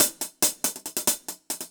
Index of /musicradar/ultimate-hihat-samples/140bpm
UHH_AcoustiHatB_140-03.wav